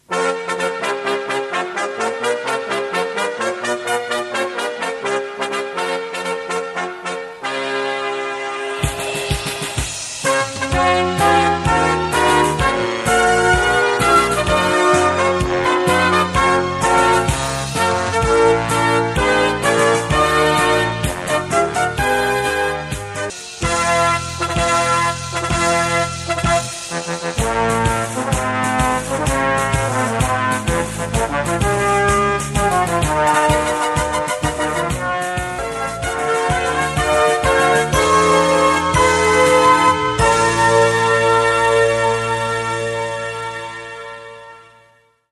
難易度 分類 並足124 時間 4分16秒
編成内容 大太鼓、中太鼓、小太鼓、シンバル、トリオ 作成No 324